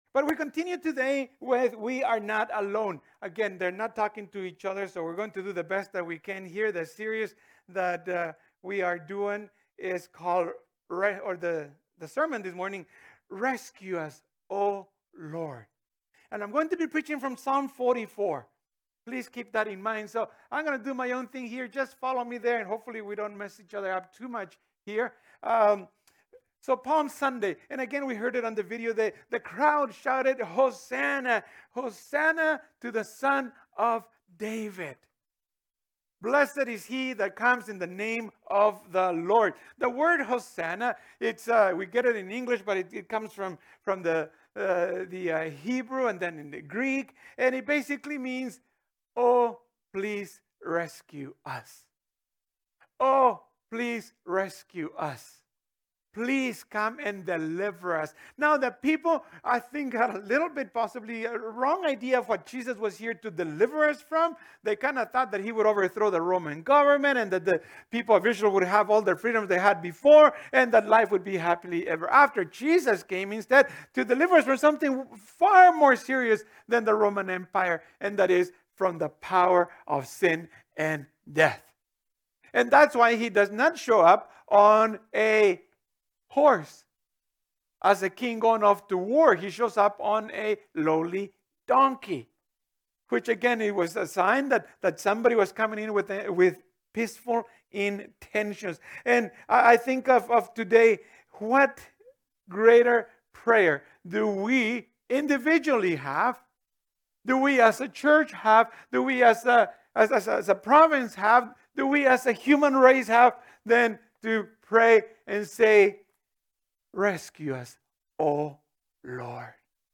[This sermon was preached on Palm Sunday 2022]